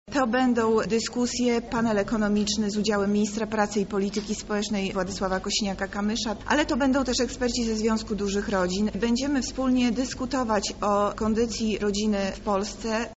O założeniach oraz przygotowanych atrakcjach mówi Monika Lipińska – zastępca prezydenta Lublina.